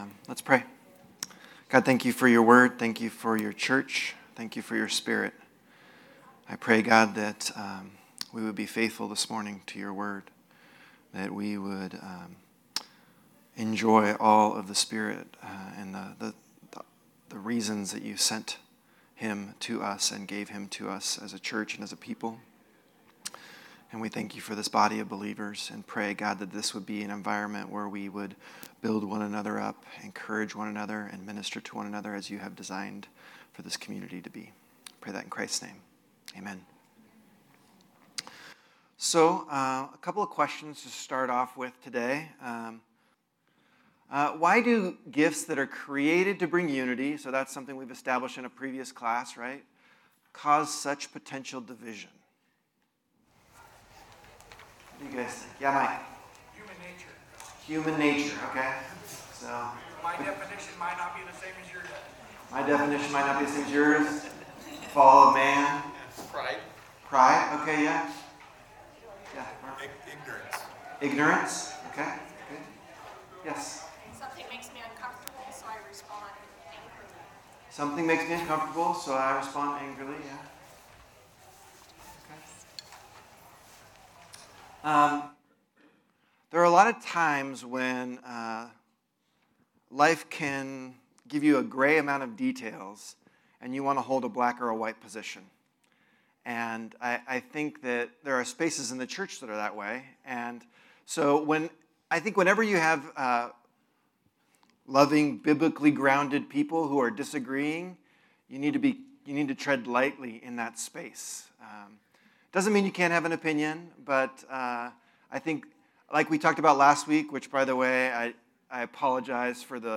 Listen to Message
Type: Sunday School